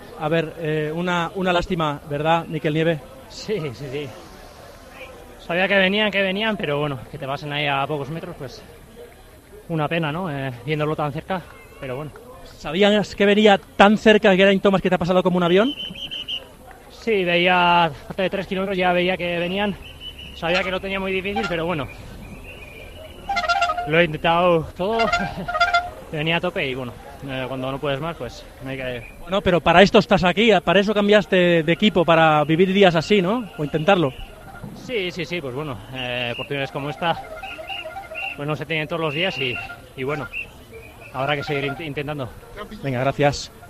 El ciclista español Mikel Nieve habló para el micrófono de COPE sobre la undécima etapa del Tour de Francia en la que se quedó a solo 200 metros de la victoria.